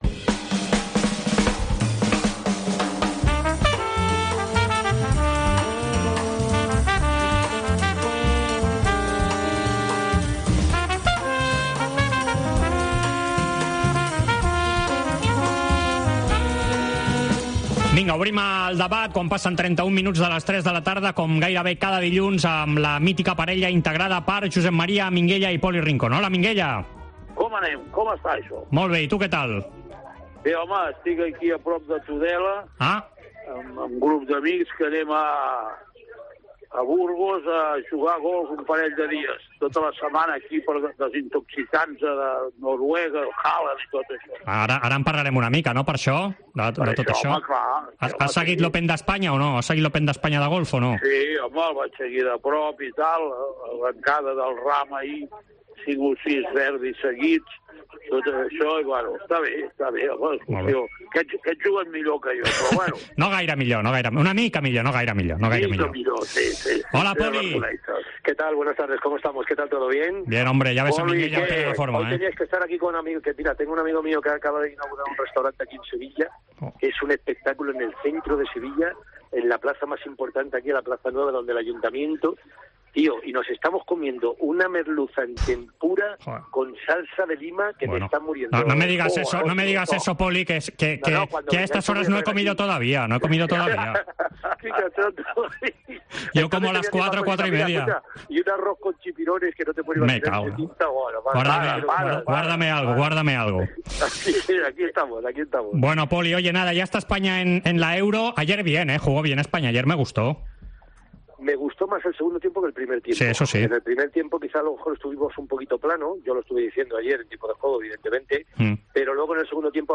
El debat Esports COPE, amb Poli Rincón i Minguella
AUDIO: Els dos col·laboradors de la Cadena COPE repassen l'actualitat esportiva d'aquesta setmana.